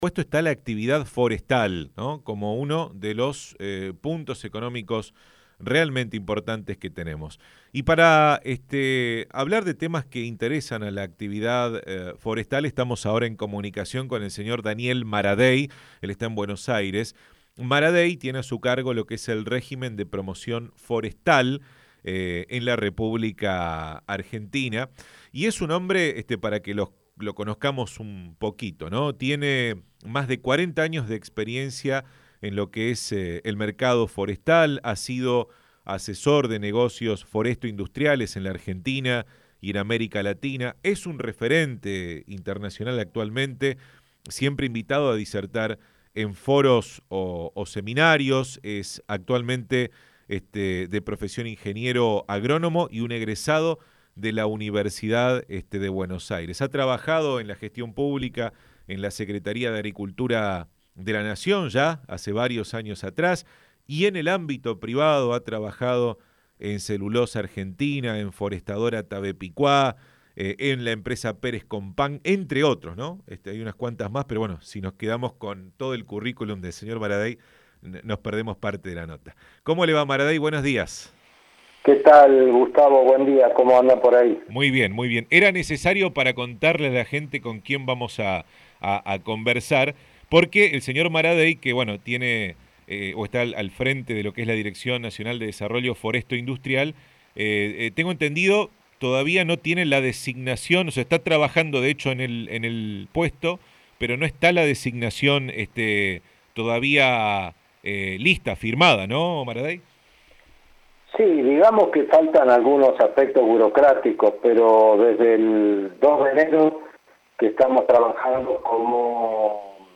En su primera entrevista periodística al frente de la Dirección Nacional de Desarrollo Foresto-industrial del Ministerio de Agricultura, dialogó con Radio Libertad, del Grupo Misiones Online, y detalló el escenario interno que atraviesan para ordenar la operatoria de la Ley 25.080 de Inversiones para Bosques Cultivados (hoy, Ley 27.487), las dificultades administrativas con las que se encontró por el volumen de expedientes, con un presupuesto anual acotado y el financiamiento voluntario del Seguro Verde que permite aún sostener el régimen de promoción forestal. Anunció los primeros pagos de planes forestales del año para productores de las provincias de Misiones y Corrientes, y adelantó un próximo crédito que favorecería a los aserraderos PyMEs ante la crisis por la pandemia.
Daniel-Maradei-FM-Radio-Libertad.mp3